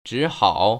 [zhĭhăo] 즈하오